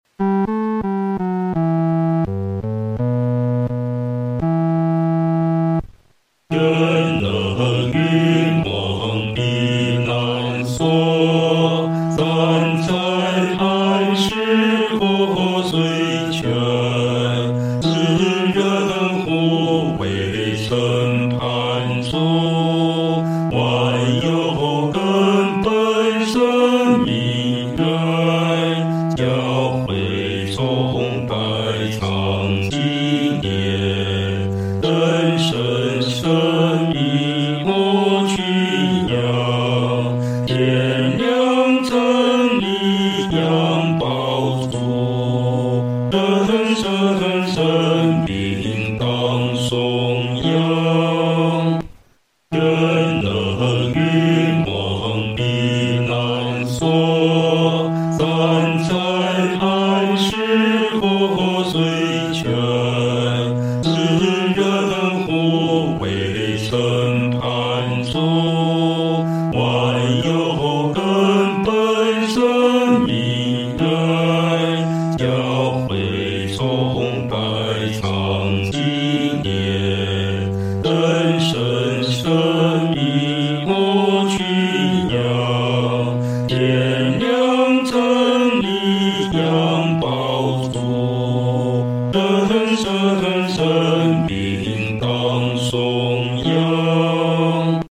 合唱
本首圣诗由网上圣诗班 (南京）录制
此曲调有壮丽的结构，旋律强健有力，节奏庄严，和声秀丽。
这首圣诗需情绪饱满，声音要求恳切。